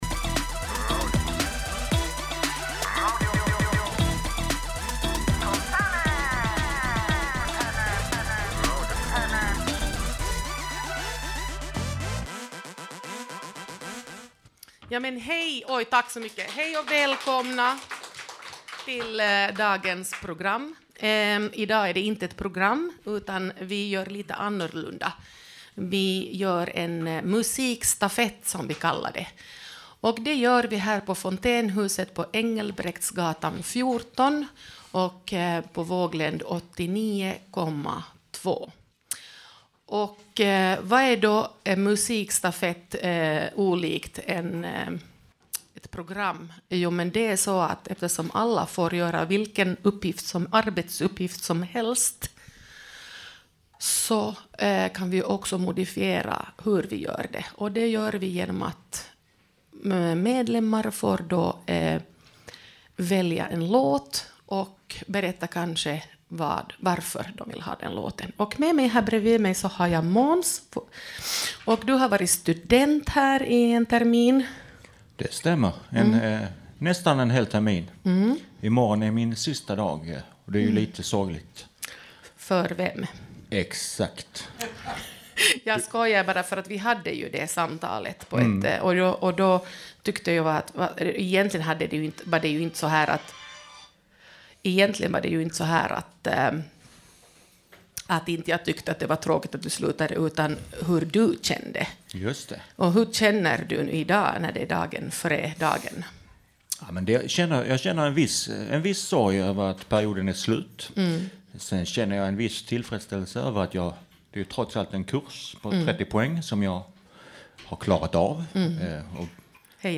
1 Årets första live: Programledarstafett! 27:53 Play Pause 2h ago 27:53 Play Pause Na później Na później Listy Polub Polubione 27:53 Nu är vi igång igen! Under årets första live delade vi på programledarjobbet och vi var ett tiotal programledare som turades om att presentera musik.